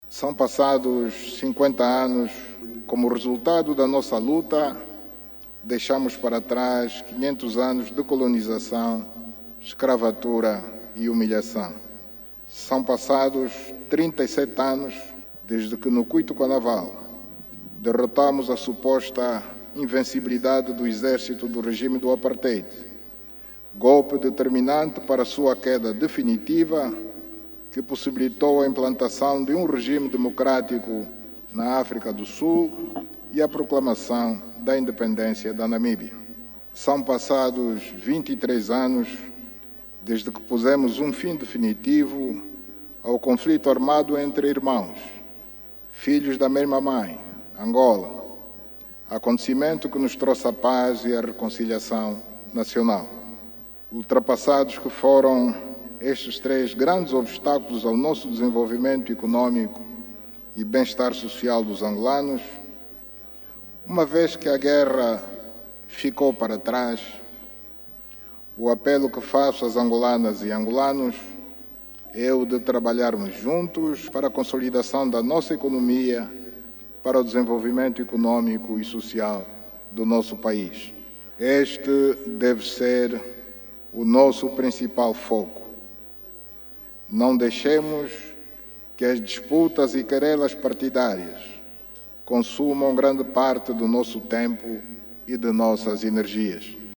Mensagem do Presidente da República, João Lourenço, proferida ontem na Praça da República, por ocasião dos 50 anos da Independência Nacional.